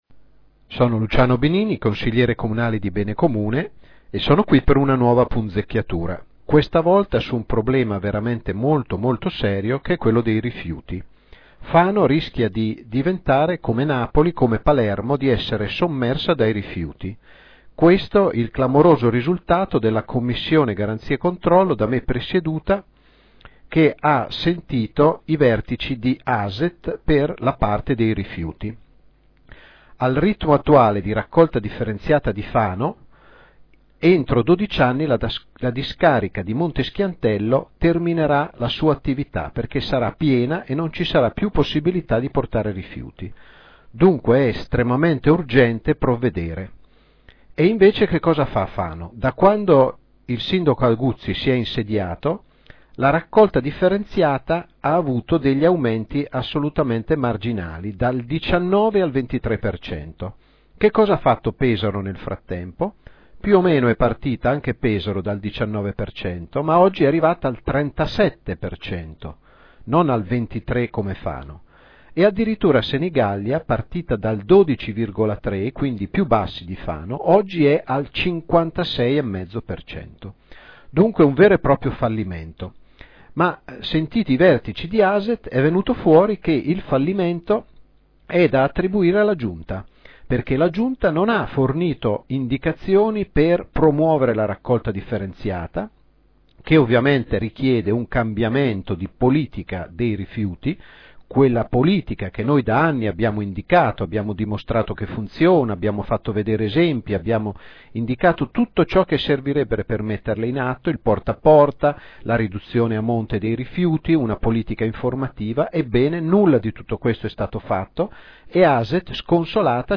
Una nuova rubrica del nostro sito in cui troverete i commenti audio su quanto sta accadendo nel nostro Comune, dentro e fuori il Consiglio.
by Luciano Benini (Consigliere Comunale BC)